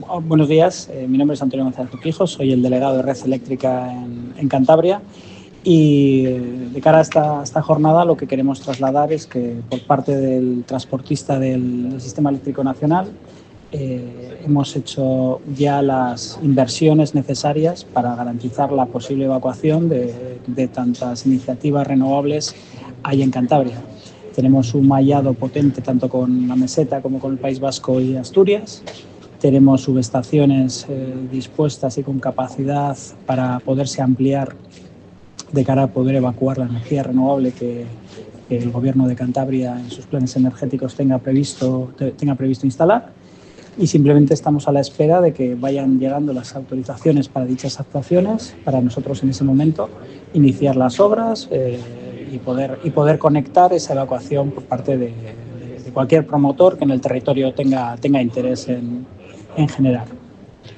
Jornada Energía en Cantabria, situación actual y renovables - CEOE-CEPYME